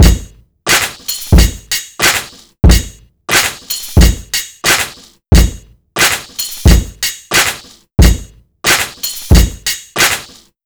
• 90 Bpm Breakbeat Sample A Key.wav
Free breakbeat sample - kick tuned to the A note. Loudest frequency: 3186Hz
90-bpm-breakbeat-sample-a-key-0YW.wav